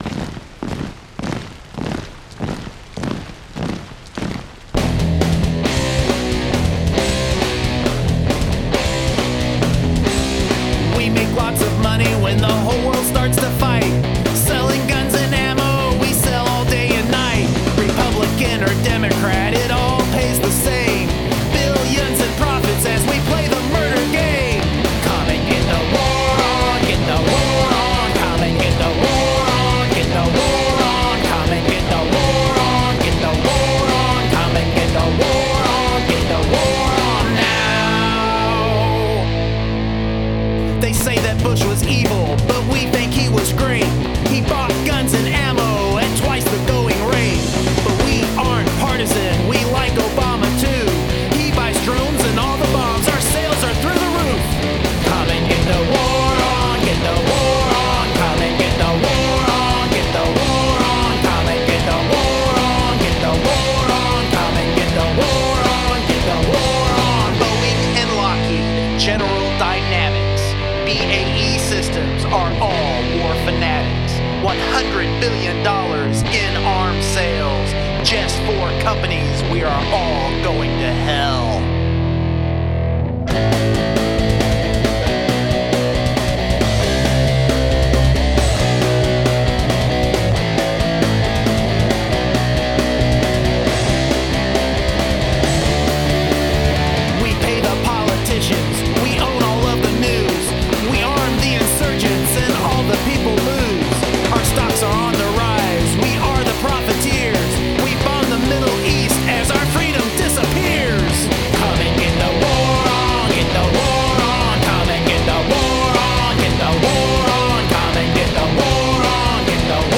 Very energetic punk touch on this, that's close to my heart! Well arranged rhythm track as well! The simple solo at 1:15 works well and sound is just fantastic!
Especially keen on the dropped beats in the chorus sections.
Punky and fresh sounding.
A nice bit of post punk here. The guitars are crunchy but clear and the vocal is not overbearing yet stands out well in the mix of things.
All clear and great mix, love the octave solo which suits the song perfectly.
Mix is fine & there's a double tracked vocal in there too, which works well.
Punky and catchy !!!